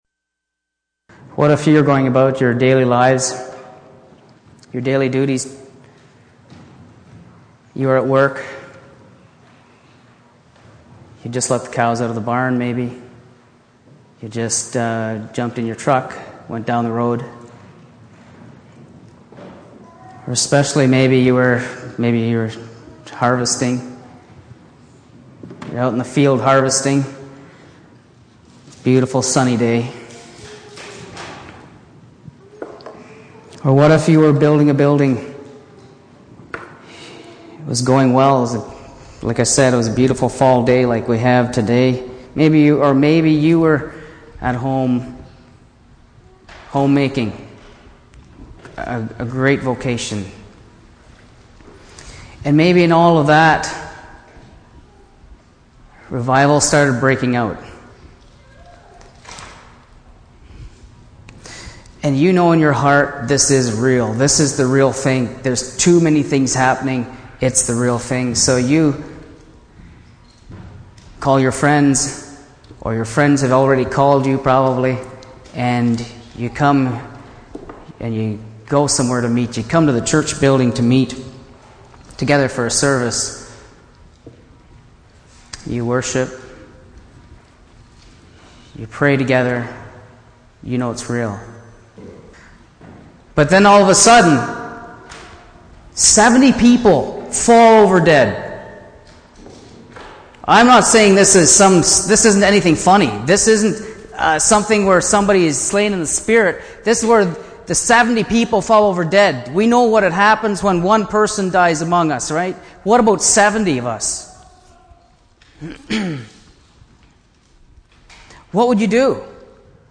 Sunday Morning Bible Study Passage: 1 Samuel 6:1-21 Service Type: Sunday Morning %todo_render% « Three Great Motivations to Love the Lord Work